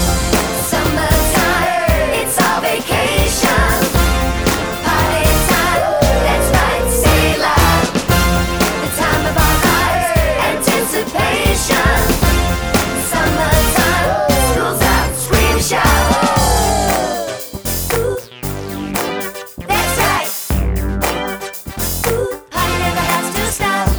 No Backing Vocals Soundtracks 3:18 Buy £1.50